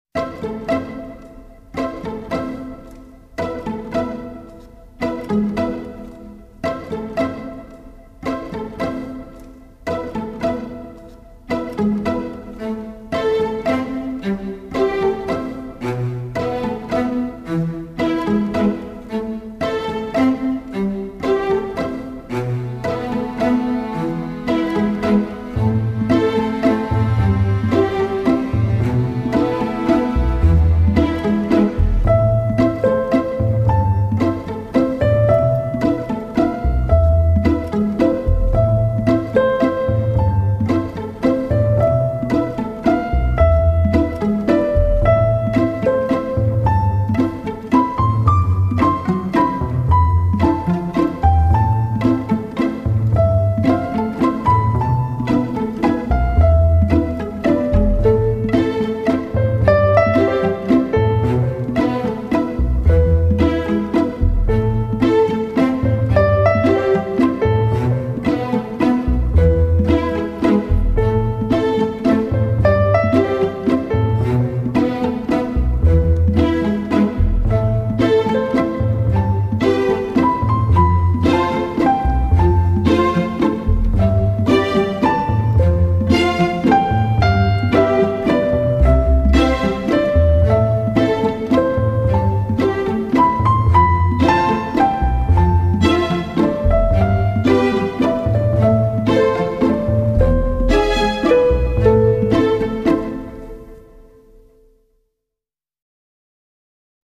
铺陈出轻缓优美的旋律，流泄出法式的浪漫情调.